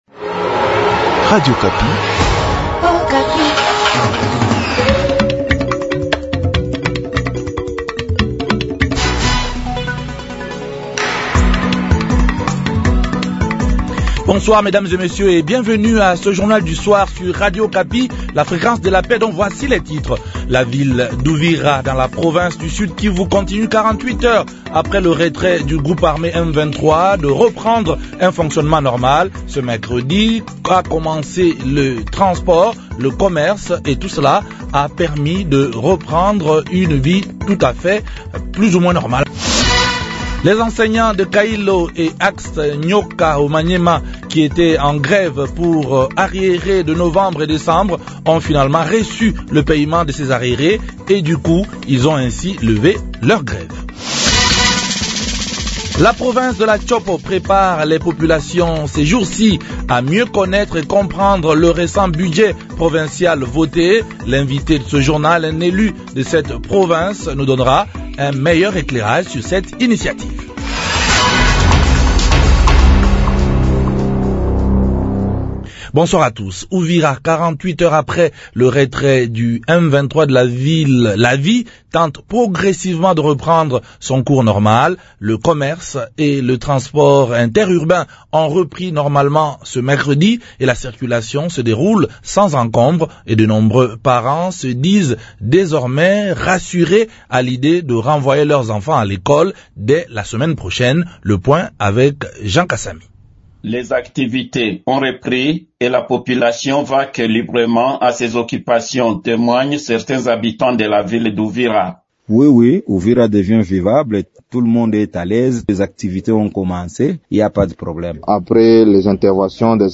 La province de la Tshopo prépare les populations ces jours ci a mieux connaitre et comprendre le récent budget provincial voté. L’invite de ce journal, un élu de cette province nous donnera un meilleur éclairage sur cette initiative.